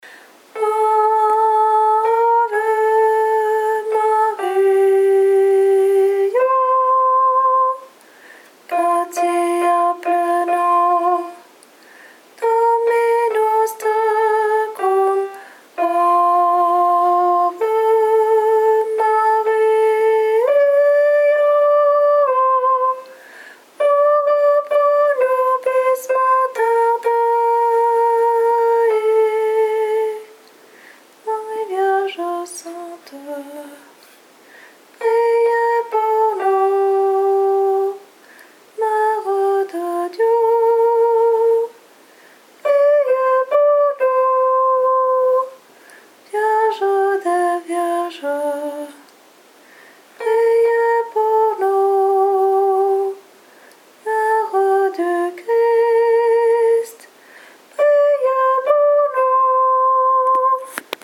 Voix chantée (MP3)COUPLET/REFRAIN
TENOR